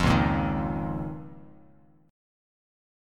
Db6add9 chord